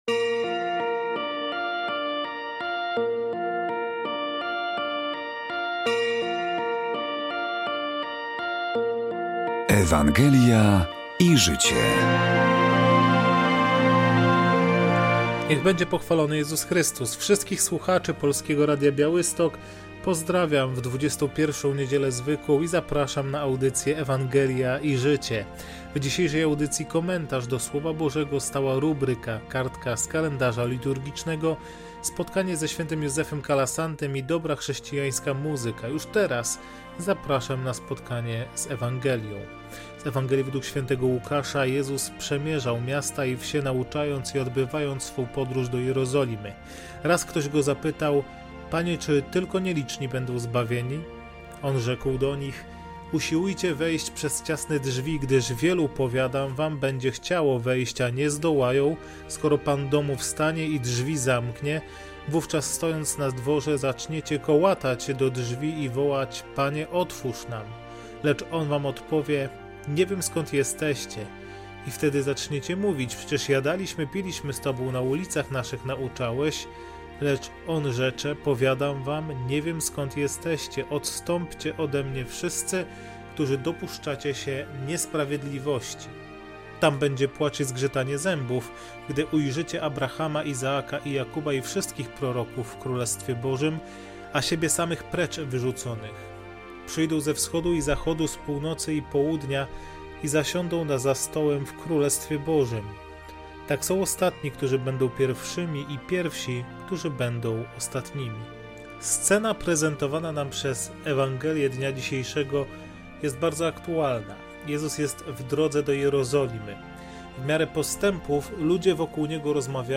W audycji rozważanie do niedzielnej Ewangelii, stała rubryka: kartka z kalendarza liturgicznego, spotkanie ze św. Józefem Kalasantym i dobra, chrześcijańska muzyka.